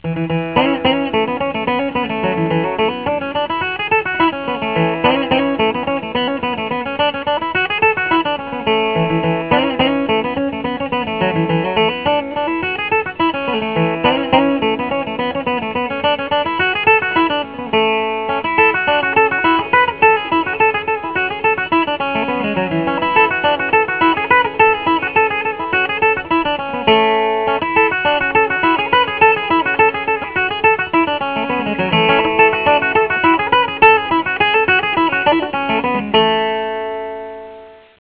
I recorded the songs right at my desk, on my Macintosh.
Fiddle and Banjo Tunes: